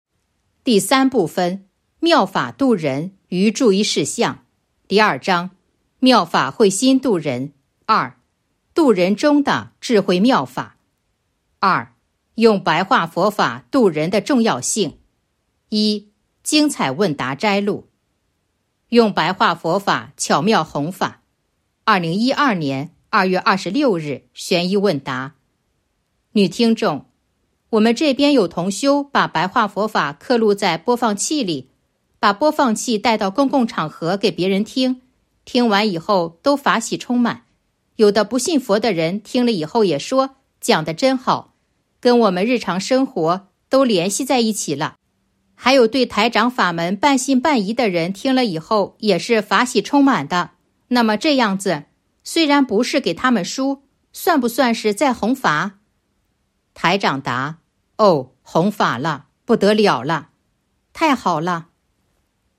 014.（二）用《白话佛法》度人的重要性 1. 精彩问答摘录《弘法度人手册》【有声书】